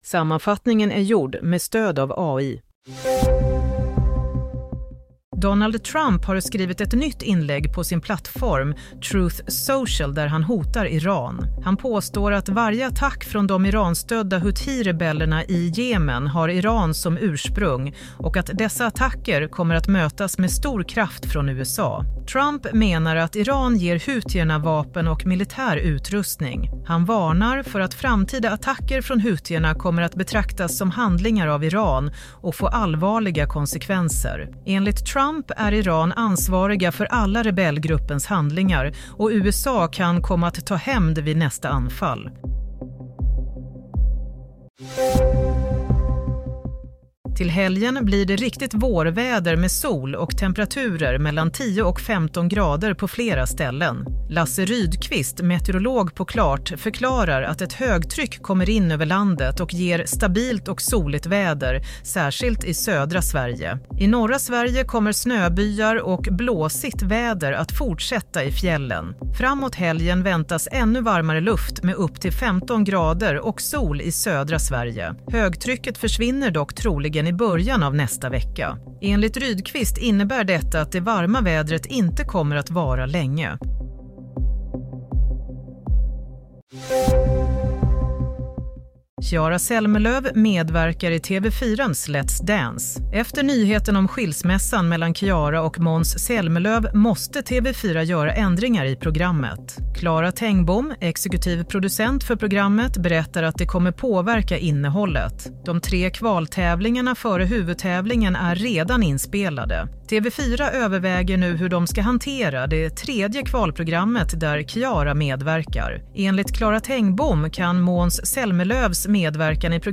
Nyhetssammanfattning - 17 mars 22:00